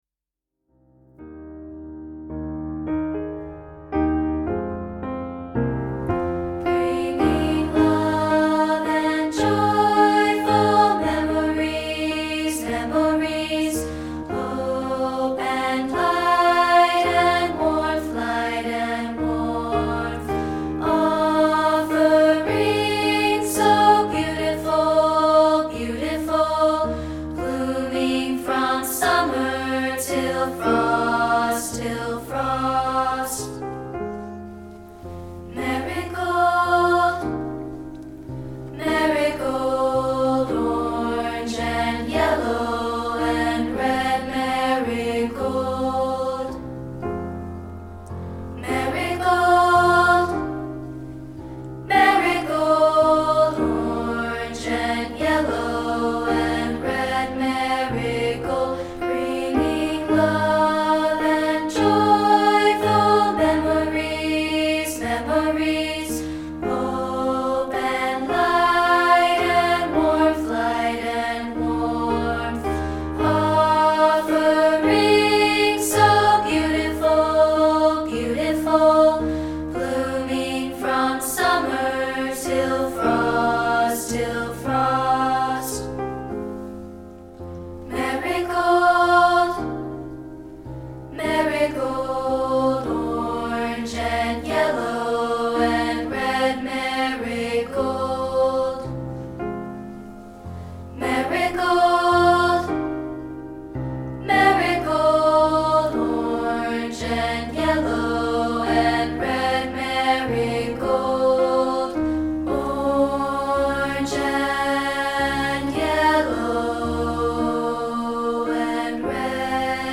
This is a rehearsal track of part 2, isolated.